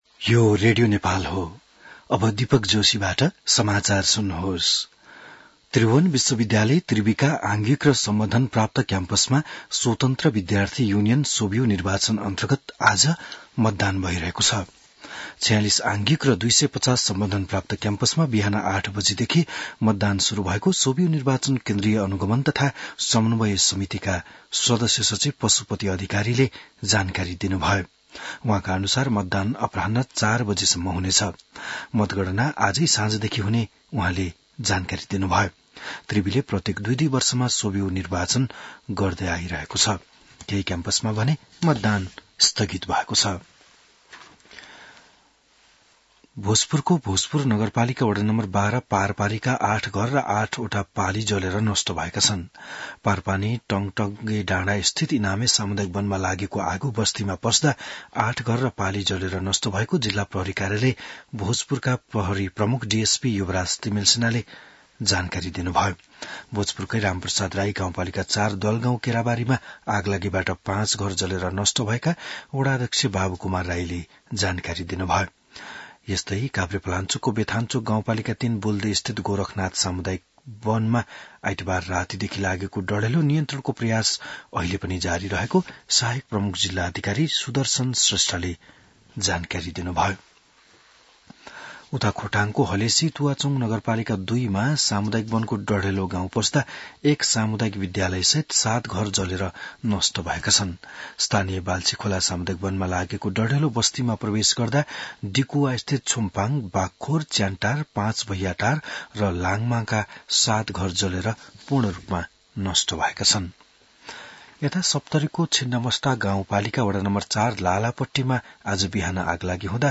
बिहान १० बजेको नेपाली समाचार : ५ चैत , २०८१